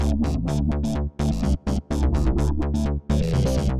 Epiano House Calm 126 E
描述：后朋克式主和弦
标签： 126 bpm Deep House Loops Piano Loops 656.42 KB wav Key : E
声道立体声